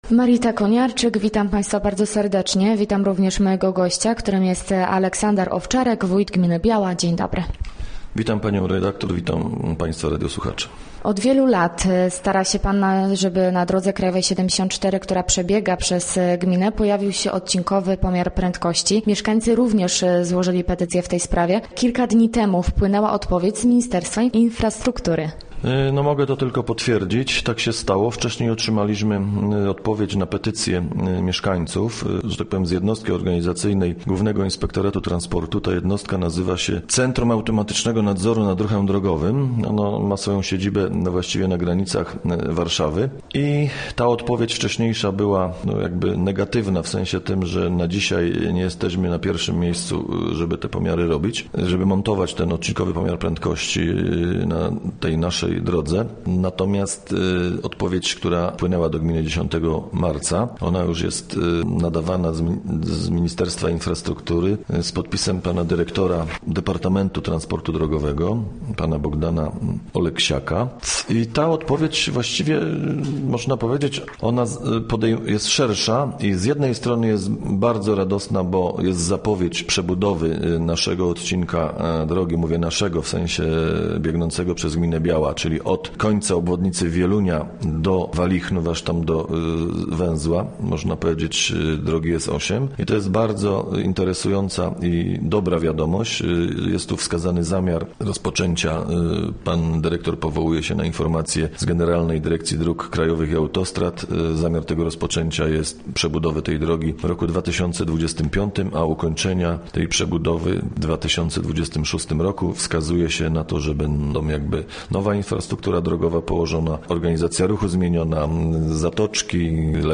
Gościem Radia ZW był Aleksander Owczarek, wójt gminy Biała